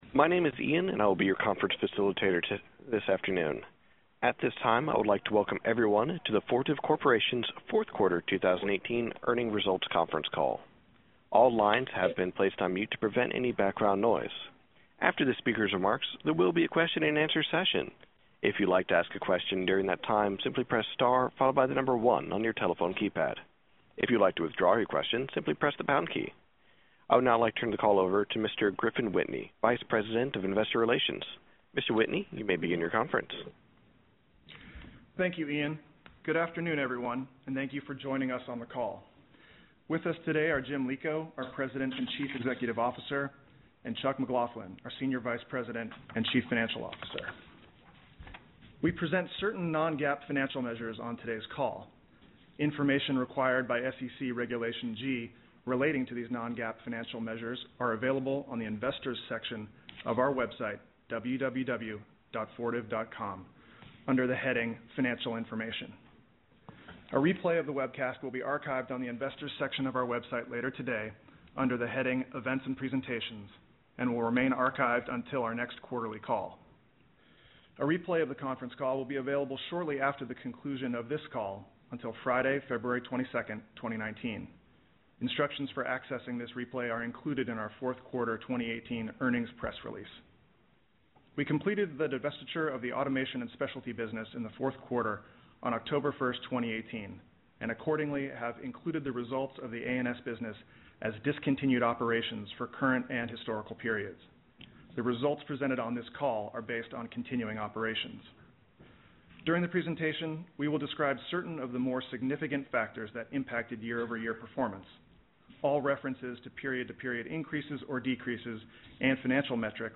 Earnings Webcast FY 2018 Audio